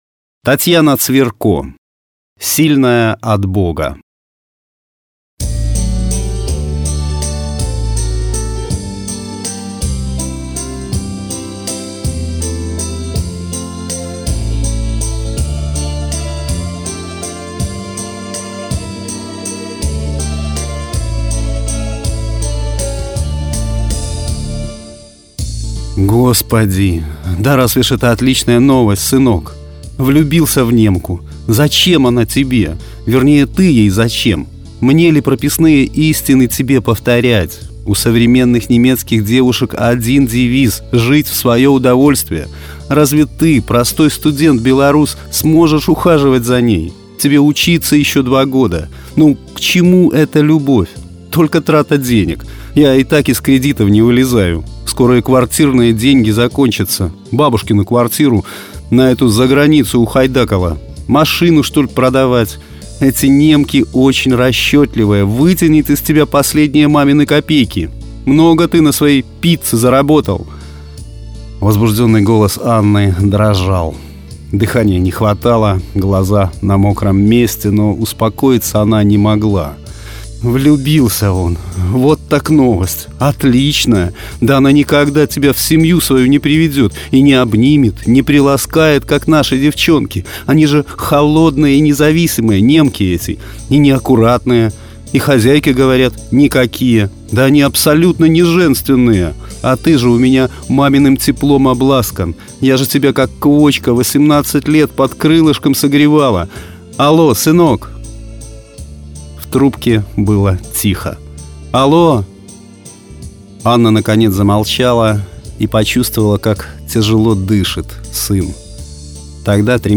Аудиорассказ
Жанр: Современная короткая проза